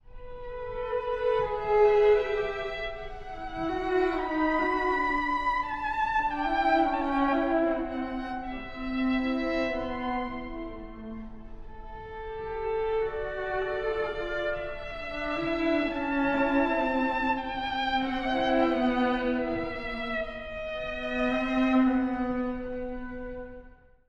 (第一主題) 古い音源なので聴きづらいかもしれません！
主題と複数の変奏によるアダージョです。
深い内省と瞑想的な美しさ。